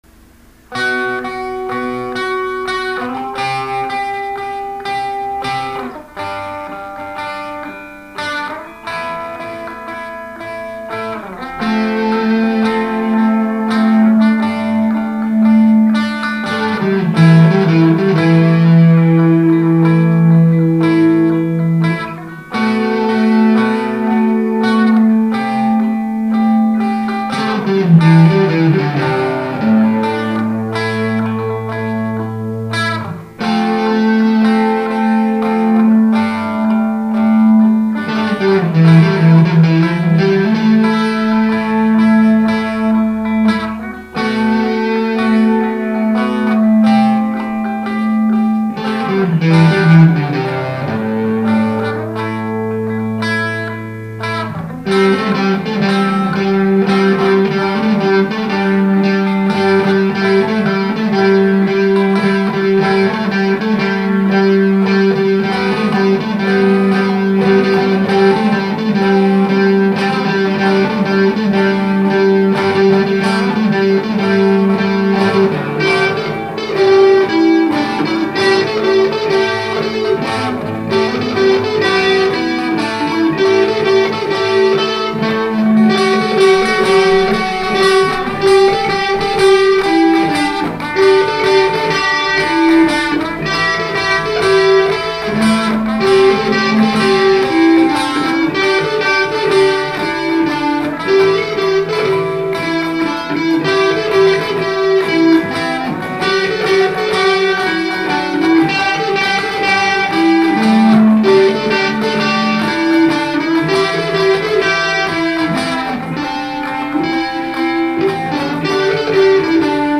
Tak, tak czytając bedzie można posłuchać muzyki relaksacyjnej. czego się nie robi dla Klientów :).